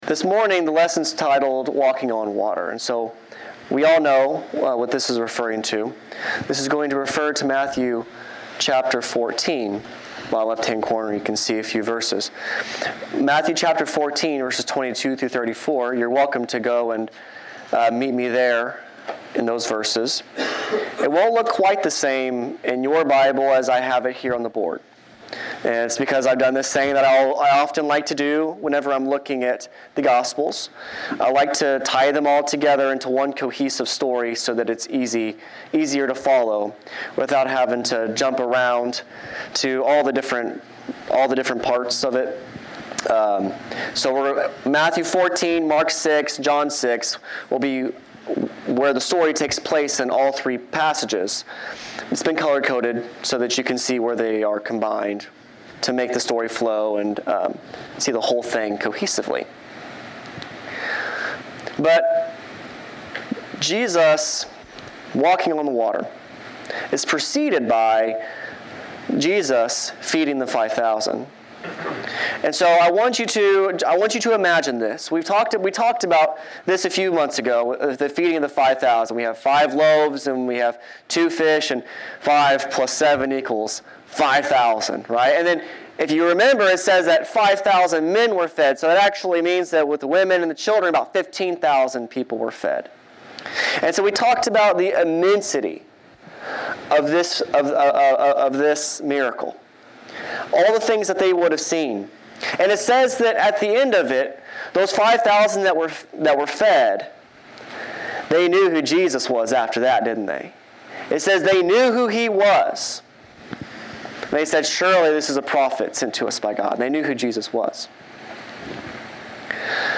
Service Type: Sunday 10:00 AM